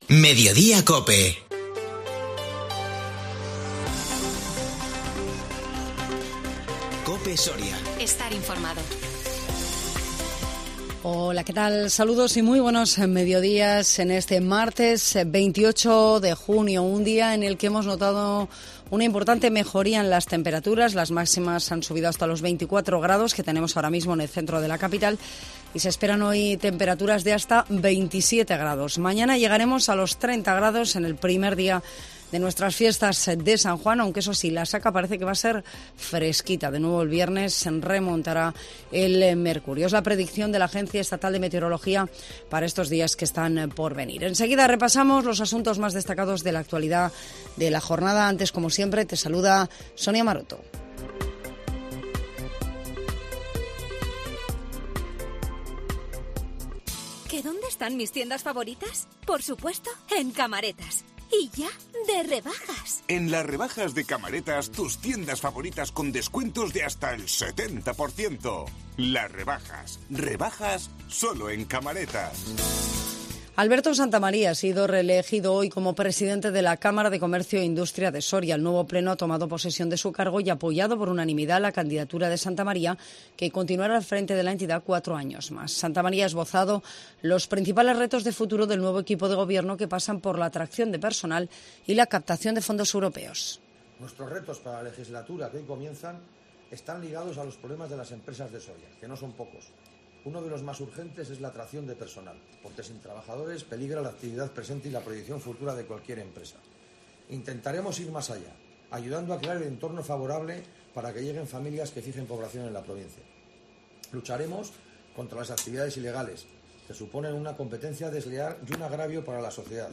INFORMATIVO MEDIODÍA COPE SORIA 28 JUNIO 2022